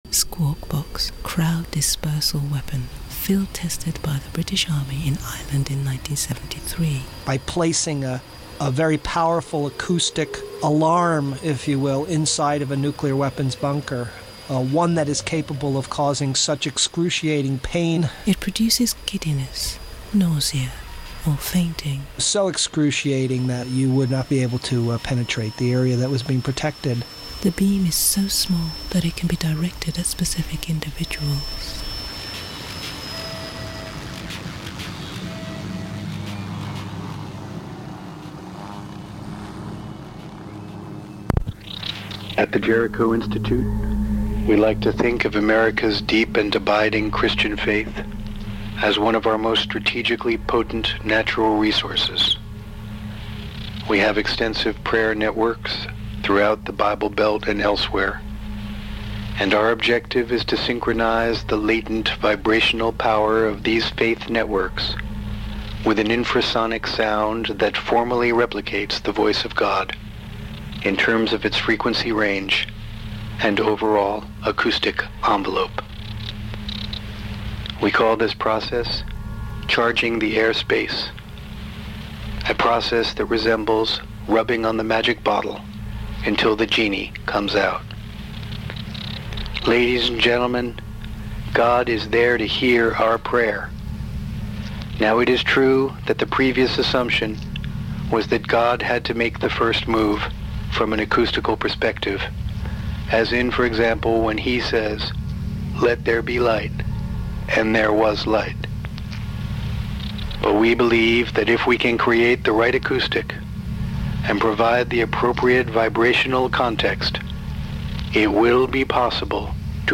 We then gradually composed the montage and mixage, bouncing tracks back and forth between our studios, on either side of the Atlantic, for a February 2006 broadcast.